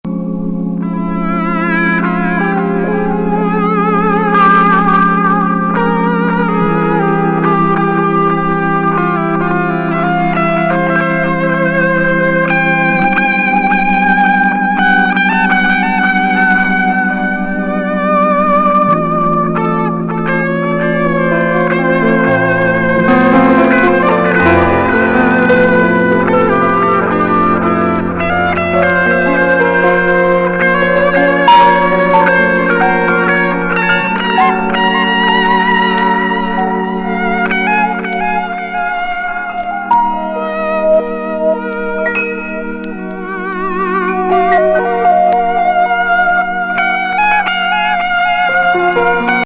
ギターをメインに、シンセサイザー、ソプラノ・サックス、フルート、ストリングス・
アンサンブル、パーカッションを駆使した一人多重録音で作り出す天上世界。